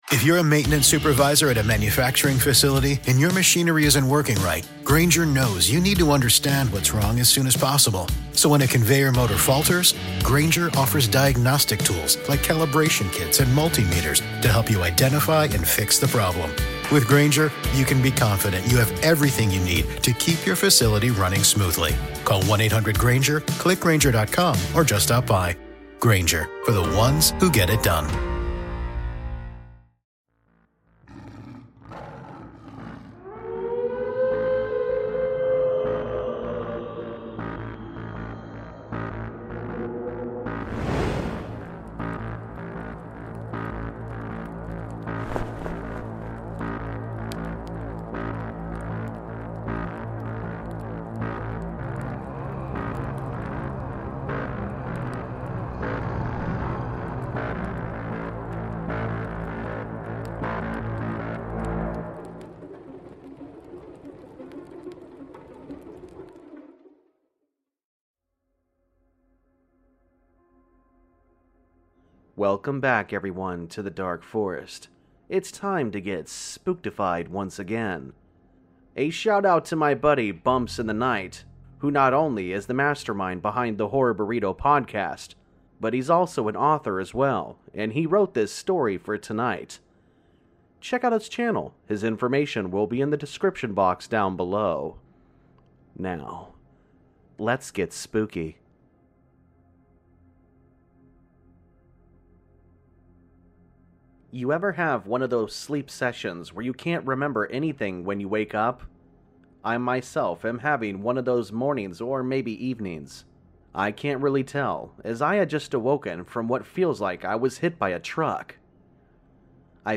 A spooky short story for this Saturday night....